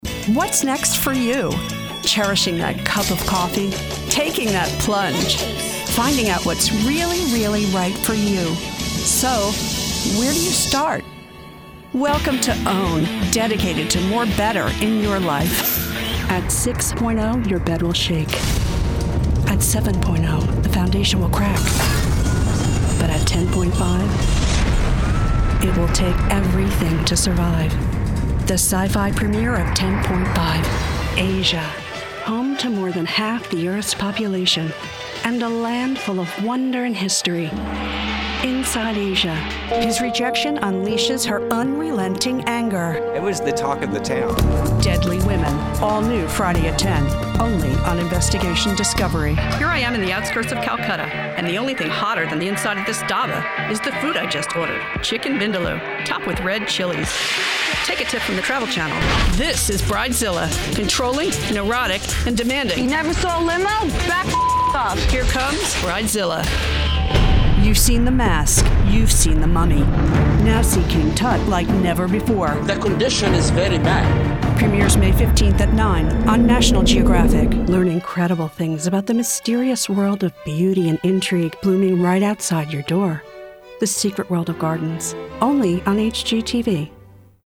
Mature Adult, Adult
Has Own Studio
standard us
promos
friendly
smooth
warm
well spoken